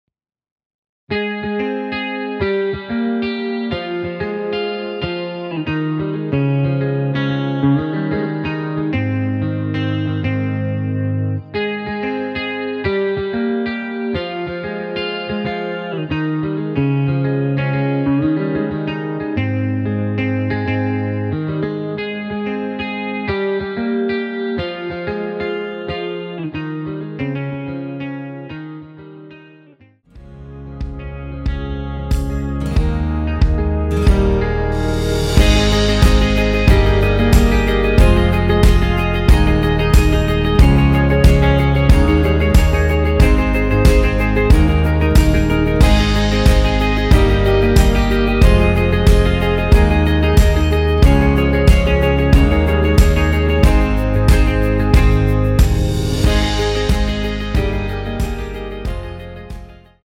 원키에서(-1)내린 MR입니다.
Ab
◈ 곡명 옆 (-1)은 반음 내림, (+1)은 반음 올림 입니다.
앞부분30초, 뒷부분30초씩 편집해서 올려 드리고 있습니다.